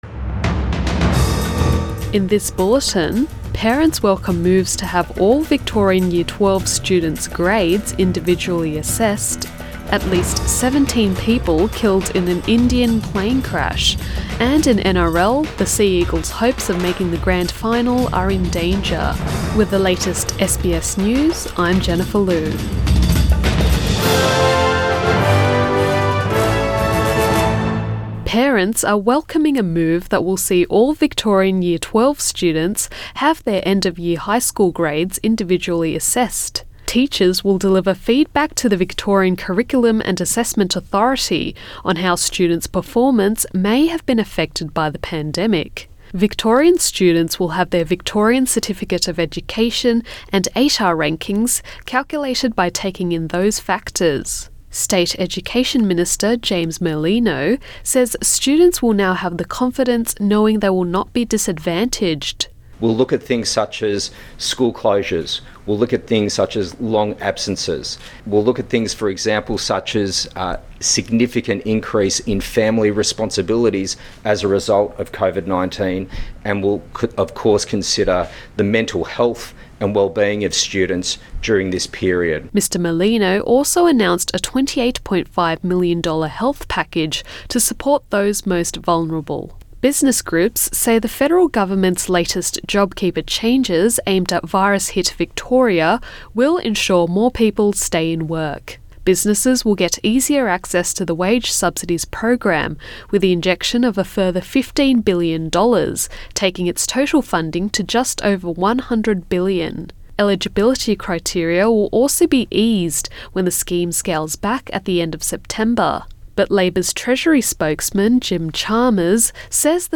AM bulletin 8 August 2020